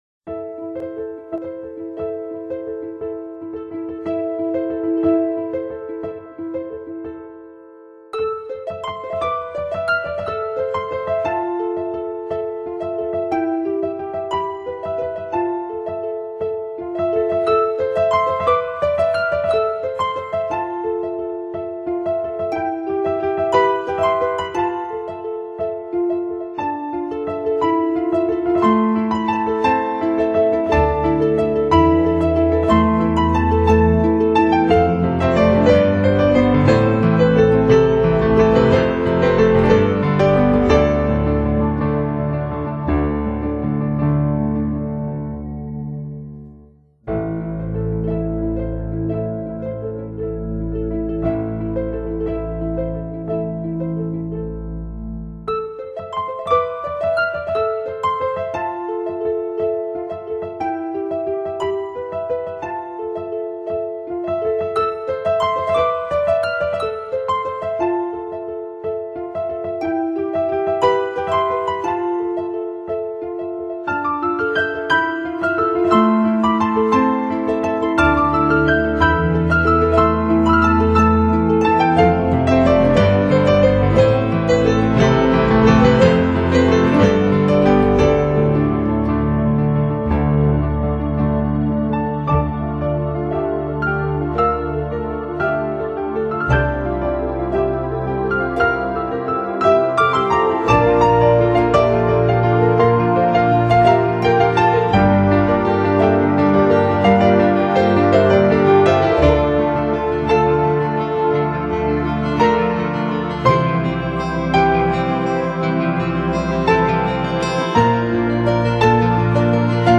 主题鲜明的钢琴演奏专辑，琢磨出钢琴静谧、激情的双面美感
最后曲式末了，场景进入月宫，空间弥漫著充满未来感和迷离感的飘渺音色。